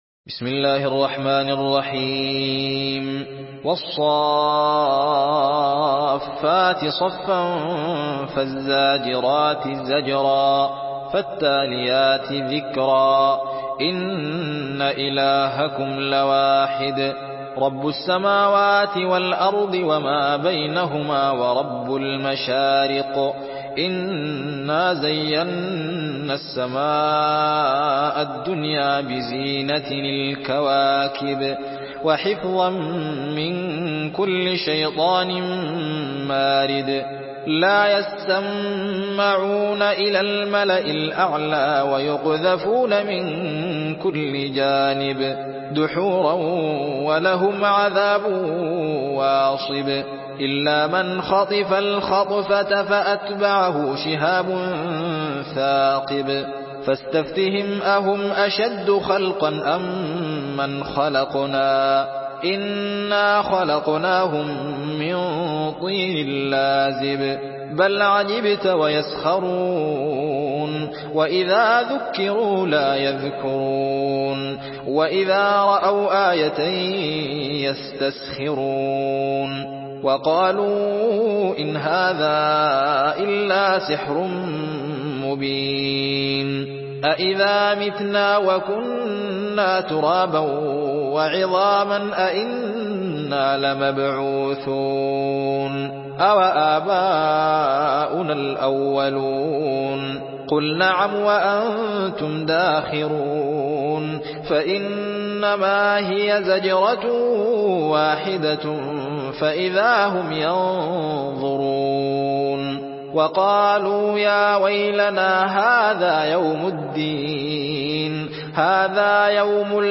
سورة الصافات MP3 بصوت الزين محمد أحمد برواية حفص عن عاصم، استمع وحمّل التلاوة كاملة بصيغة MP3 عبر روابط مباشرة وسريعة على الجوال، مع إمكانية التحميل بجودات متعددة.
مرتل